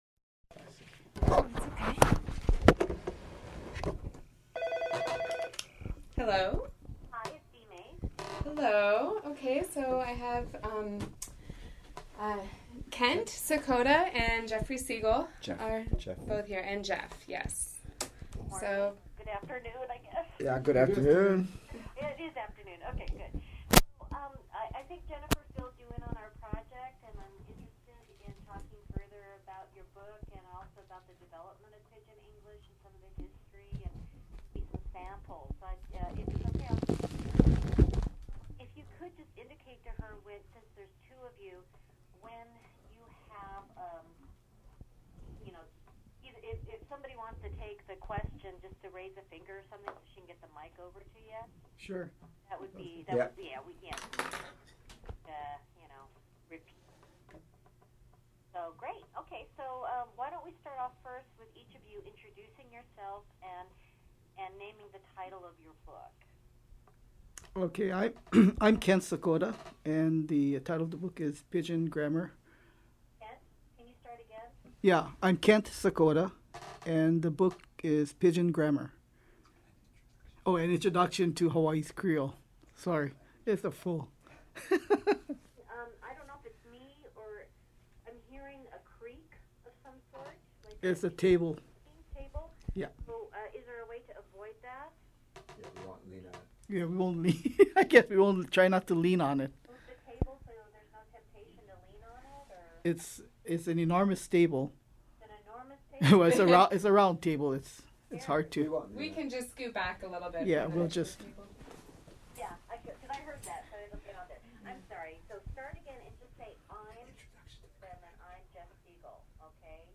Transcript Pidgin Grammar Interview w